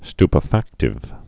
(stpə-făktĭv, sty-)